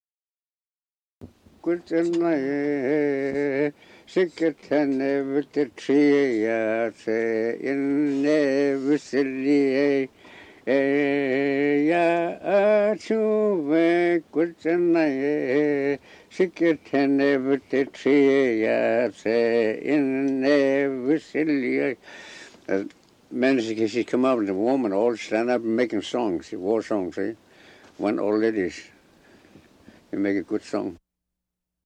War Song